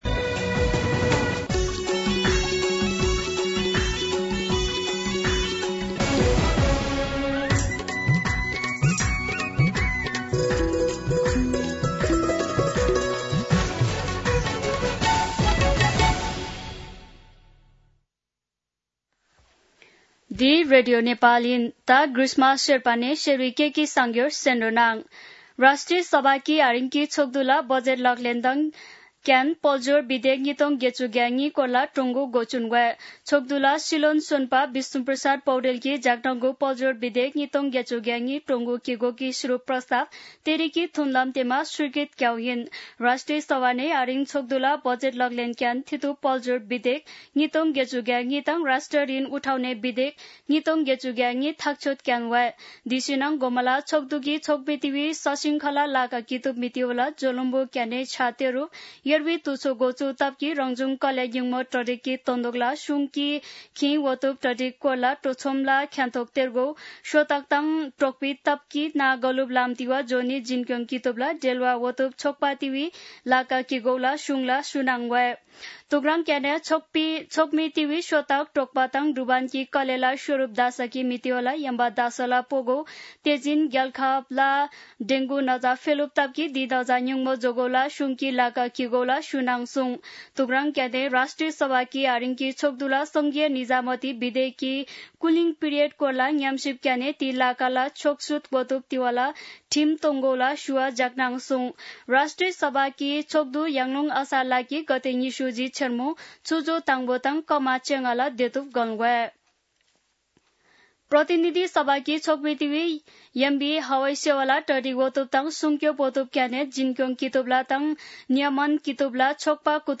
शेर्पा भाषाको समाचार : २० असार , २०८२
Sherpa-News-3-20.mp3